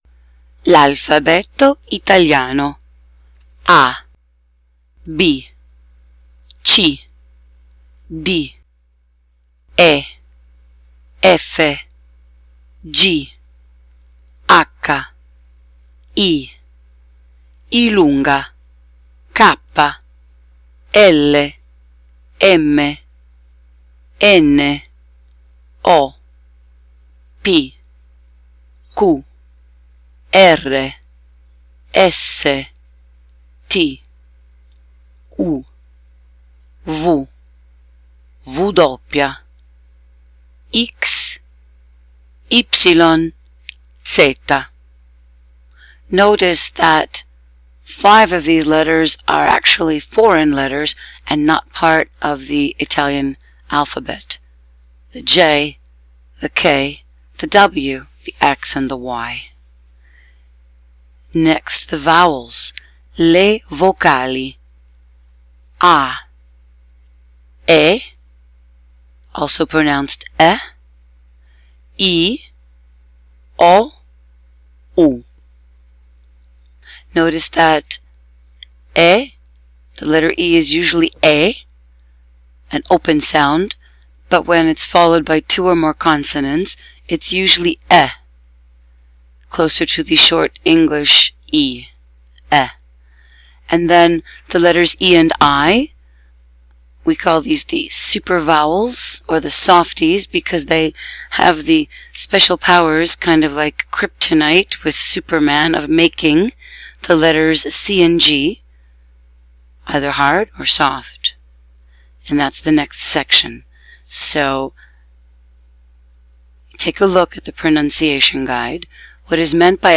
Italian Alphabet & Special Sounds
Phonetic Pronunciation Guide (letters between slash marks / / indicate the English phonetic pronunciation)